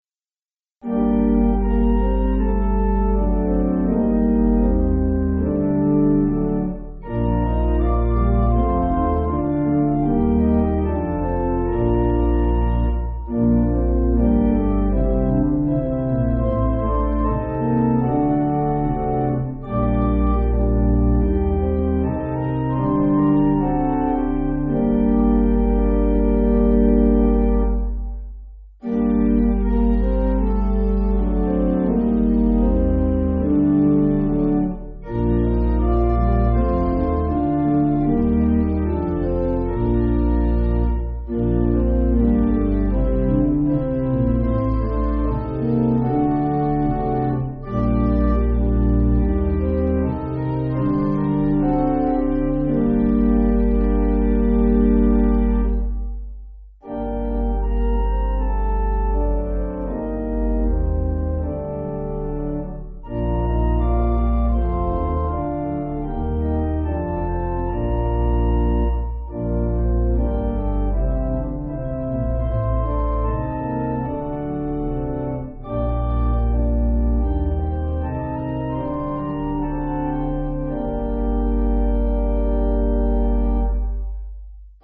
(CM)   6/Gm
Quieter